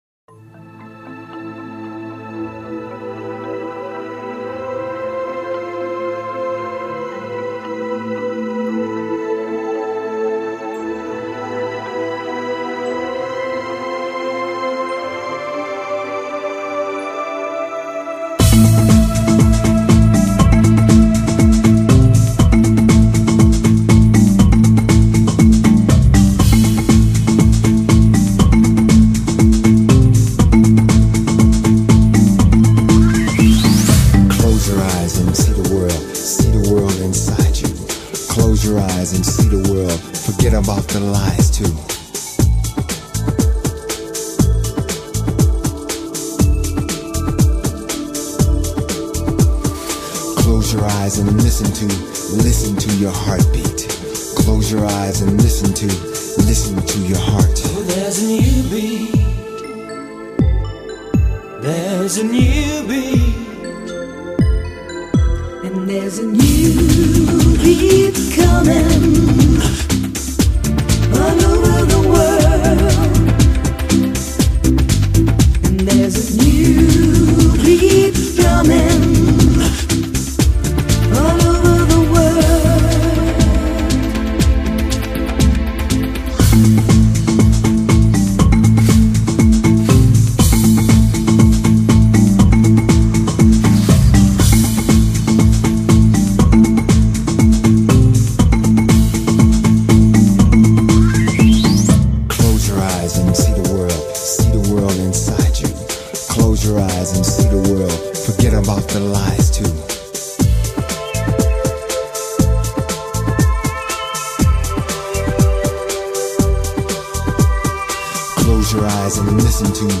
那种原始的鼓声，脚链声
低回震颤的民族式唱腔，自由跳动的鼓声，桀骜不驯的打击乐